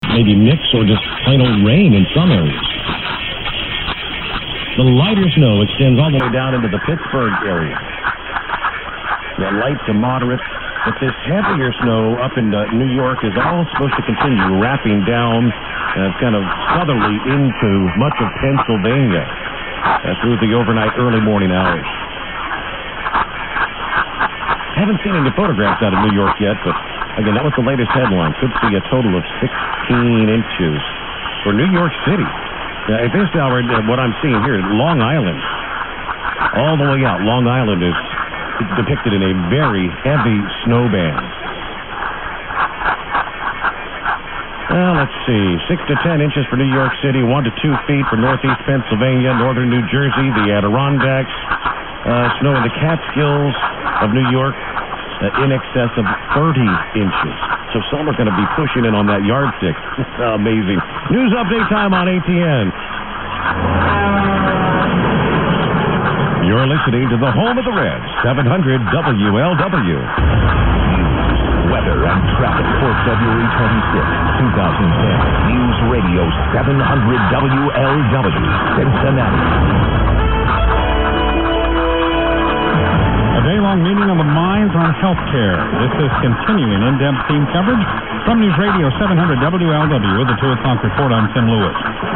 Playing twisting the night away, followed by a station ID.
091212_0828_800_chab_moose_jaw_sk_ssb_then_am.mp3